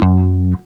Bass (14).wav